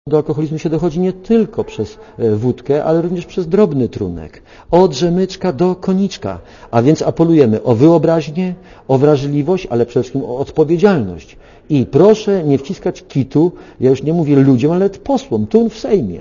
Reklama wina spowoduje, że jeszcze więcej młodych ludzi będzie sięgało po kieliszek - odpowiada Tadeusz Cymański z PiS-u, recytuje nawet wierszyk na tę okoliczność.
Komentarz audio